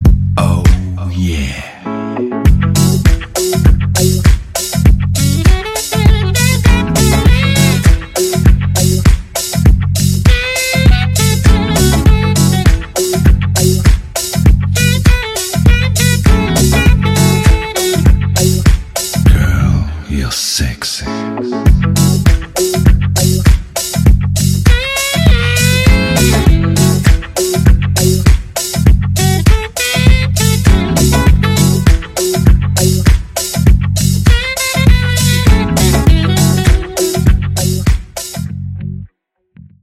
Саксофон , Рингтоны техно
chillout
nu disco
Soulful